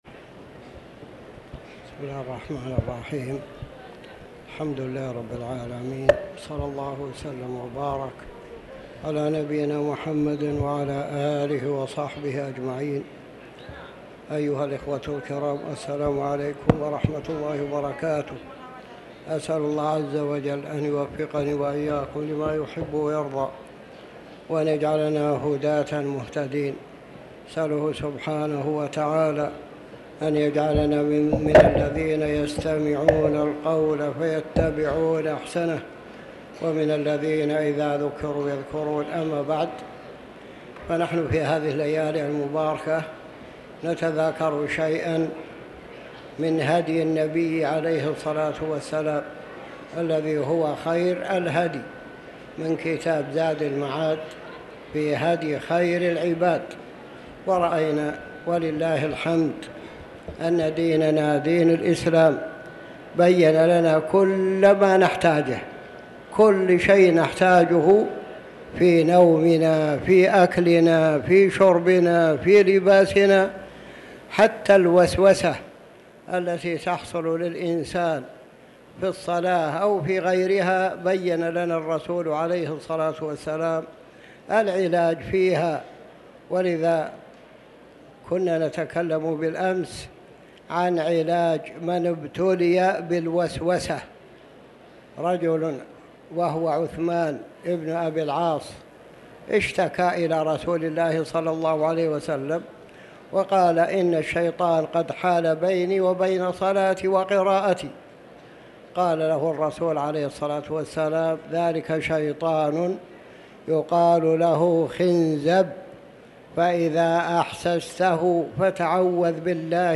تاريخ النشر ١٩ ذو الحجة ١٤٤٠ هـ المكان: المسجد الحرام الشيخ